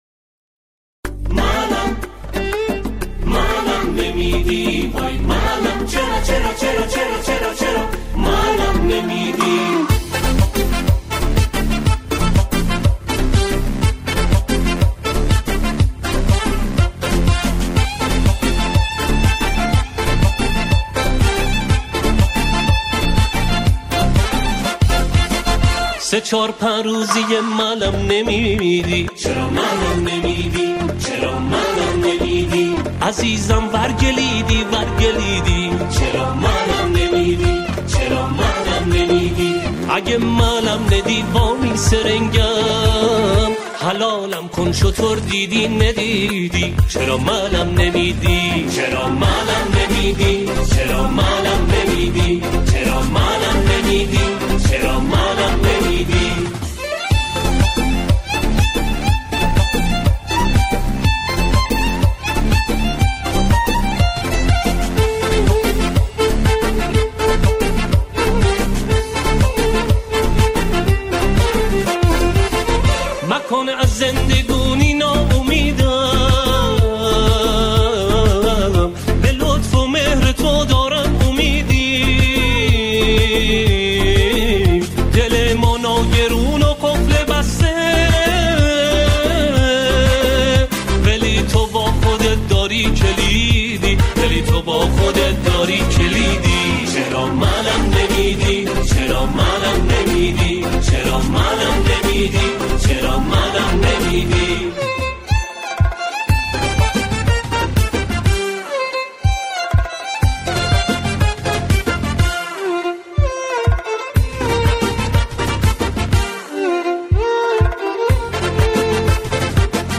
همخوانان، این قطعه را با شعری عاشقانه اجرا می‌کنند.
به گویش کرمانی سروده است.